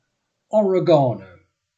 Ääntäminen
IPA : /ɒɹɪˈɡɑːnəʊ/ IPA : /əˈɹɛɡənəʊ/ GA : IPA : /əˈɹɛɡənoʊ/